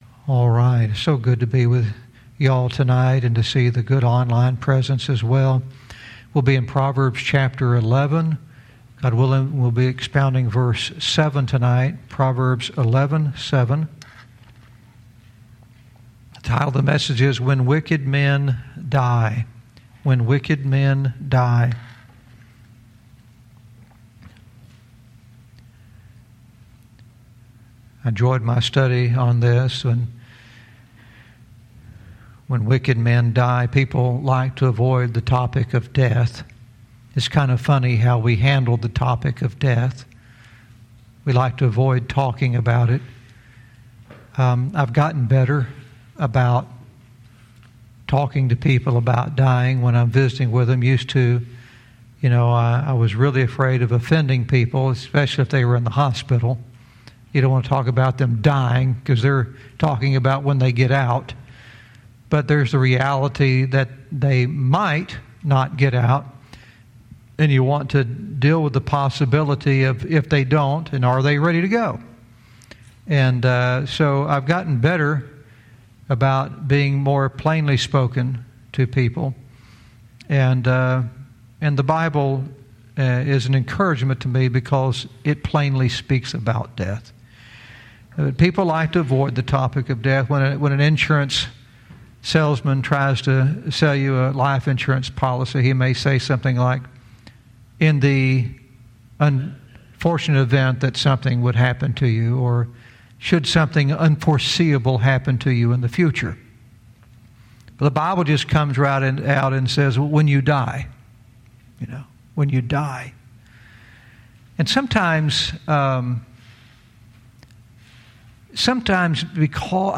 Verse by verse teaching - Proverbs 11:7 "When Wicked Men Dies"